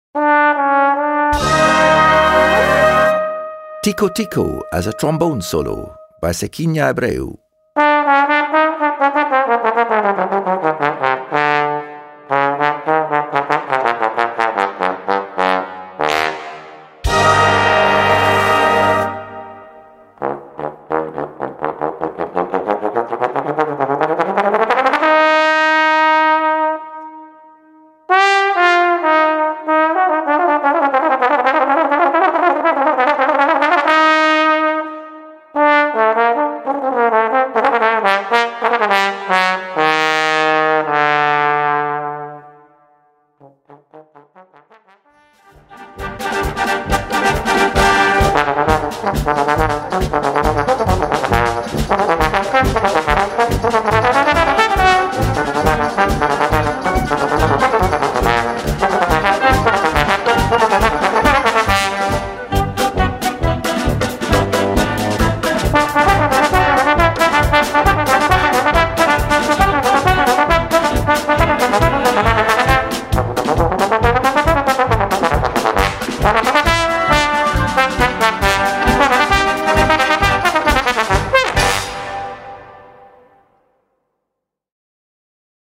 Solo für Posaune und Blasorchester
Besetzung: Blasorchester